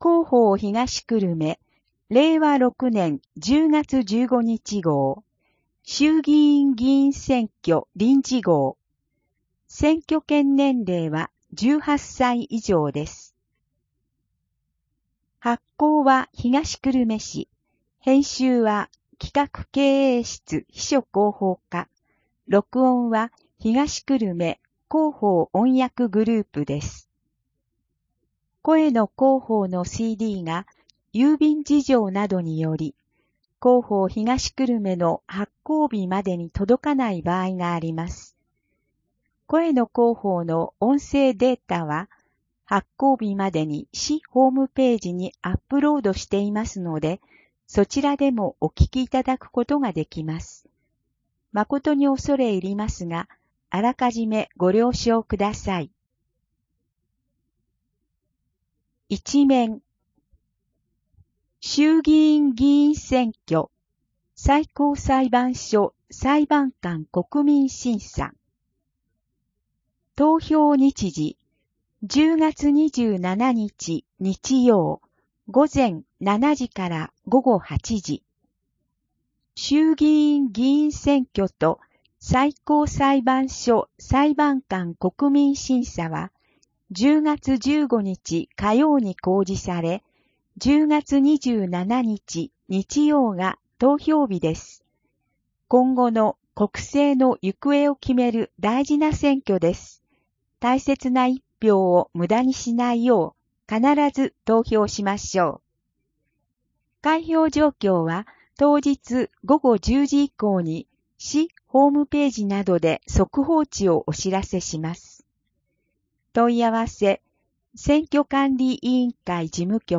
声の広報（令和6年10月15日号選挙臨時号）